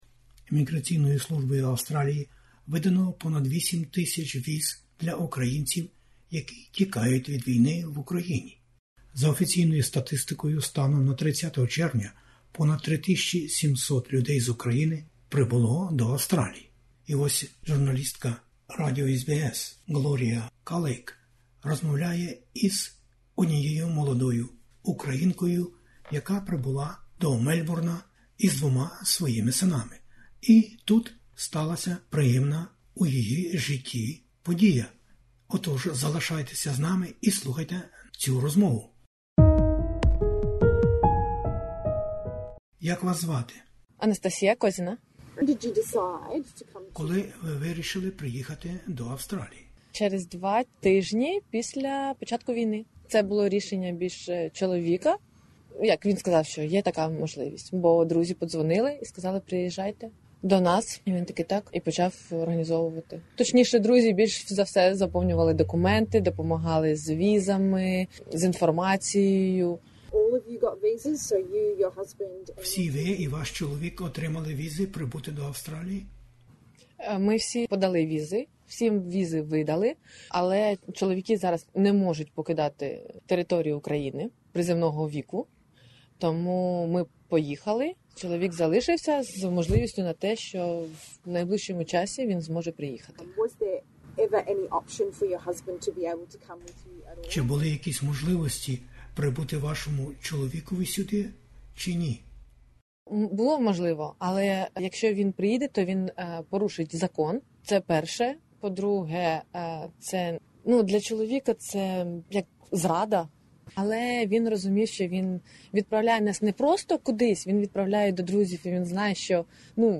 Війна в Україні розкидає людей по планеті усій. Далі слухайте розповідь українки, котра, втікаючи від війни із двома синами, нещодавно прибула до Австралії. Тут лише деякі акценти із життя-буття молодої сім'ї у перші місяці у новій країні...